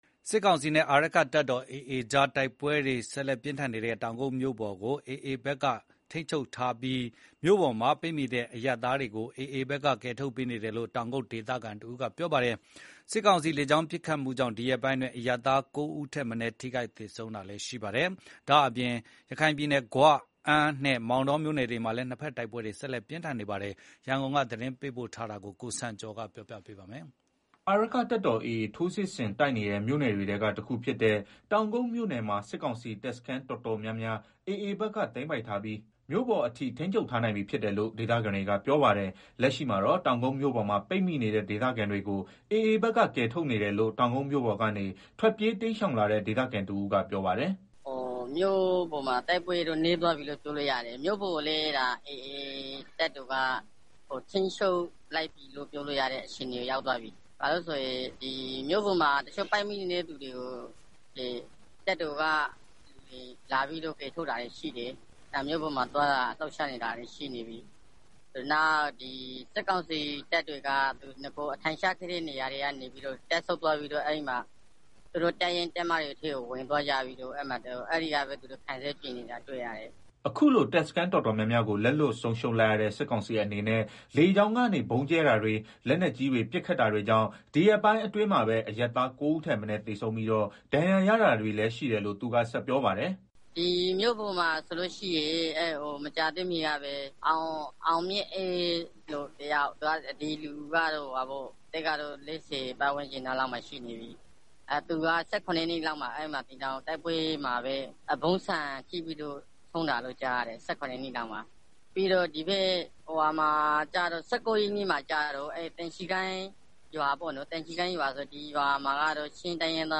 အာရက္ခတပ်တော် AA ထိုးစစ်ဆင်တိုက်နေတဲ့ မြို့နယ်တွေထဲက တခုဖြစ်တဲ့ တောင်ကုတ်မြို့နယ်မှာ စစ်ကောင်စီတပ်စခန်းတော်တော်များများ AAဘက်ကသိမ်းပိုက်ထားပြီး မြို့ပေါ်အထိ ထိန်းချုပ်ထား နိုင်ပြီဖြစ်တယ်လို့ ဒေသခံတွေကပြောပါတယ်။ လက်ရှိမှာတော့ တောင်ကုတ်မြို့ပေါ်မှာ ပိတ်မိနေတဲ့ ဒေသခံတွေကို AA ဘက်က ကယ်ထုတ်နေတယ်လို့တောင်ကုတ်မြို့ပေါ်ကနေ ထွက်ပြေး တိမ်းရှောင် လာတဲ့ ဒေသခံတဦးကပြောပါတယ်။